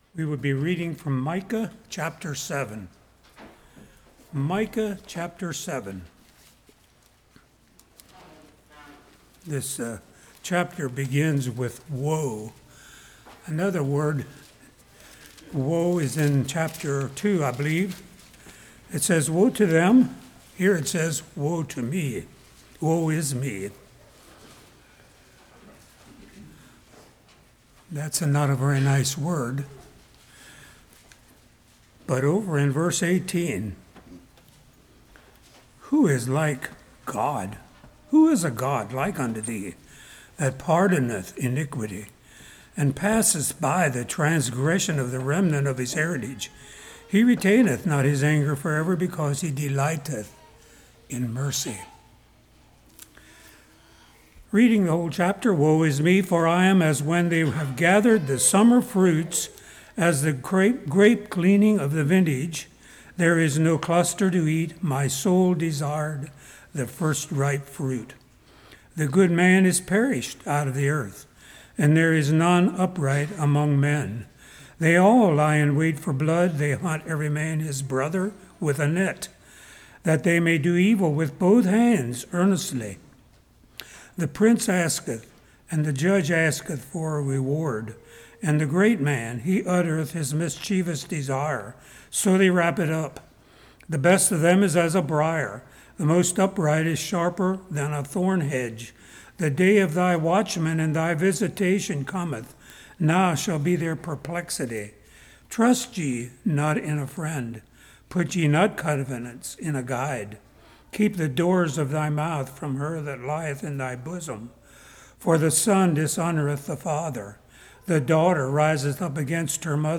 Micah 7:1-20 Service Type: Morning God’s Pardon God’s Mercy and Compassion God’s Promises Love paid for Mercy « Do We Have the Right Mindset?